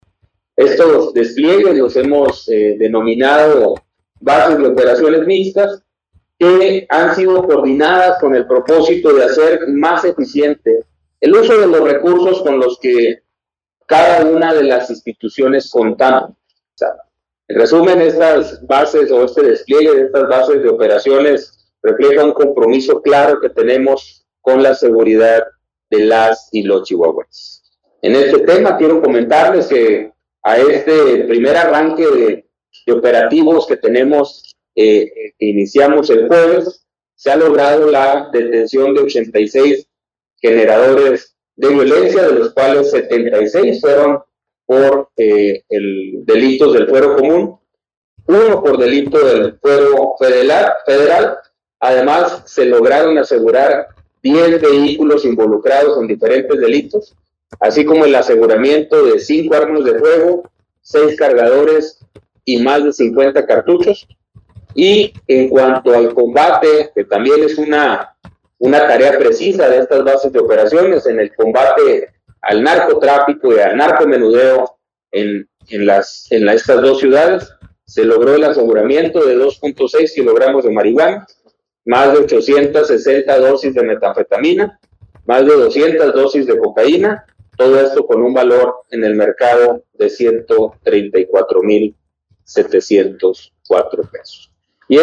AUDIO: GILBERTO LOYA CHÁVEZ, SECRETARIO DE SEGURIDAD PÚBLICA DEL ESTADO (SSPE)